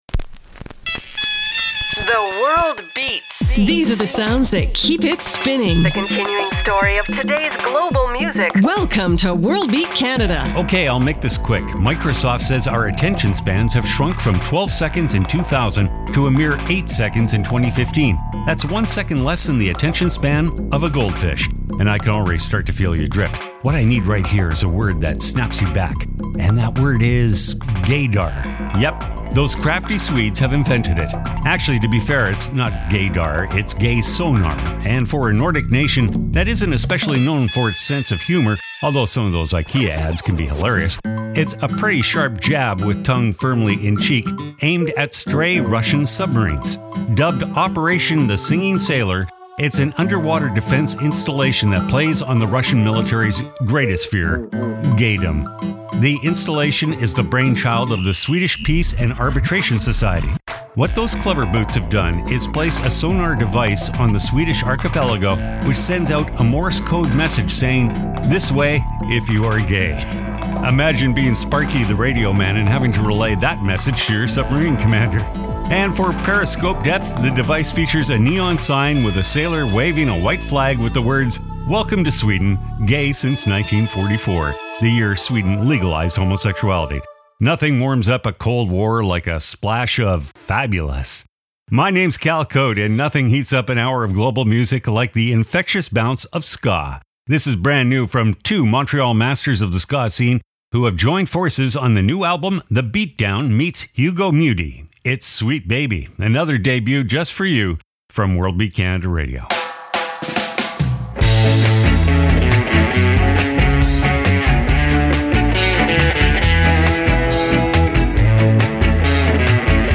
exciting contemporary global music alternative to jukebox radio